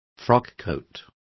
Also find out how levita is pronounced correctly.